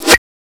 THWACK.WAV